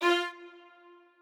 strings9_26.ogg